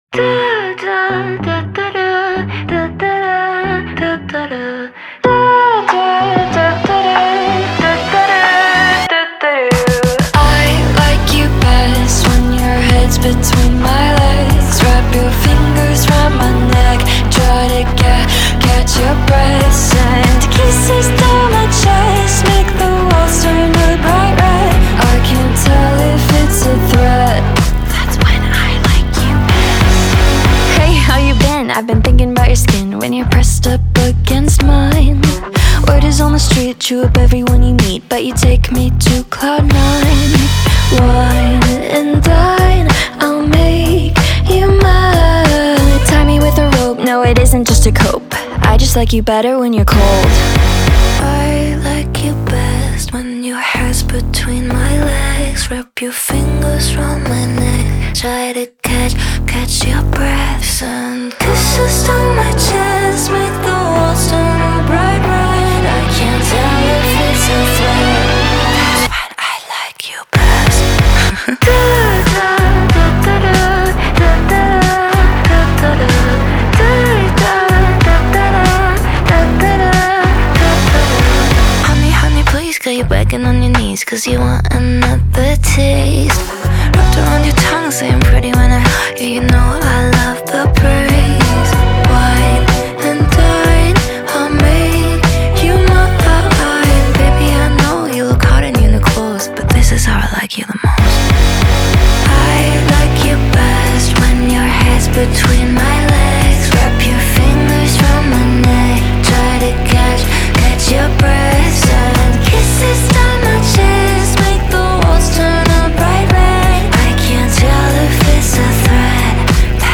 BPM94-94
Audio QualityPerfect (High Quality)
Alt Pop song for StepMania, ITGmania, Project Outfox
Full Length Song (not arcade length cut)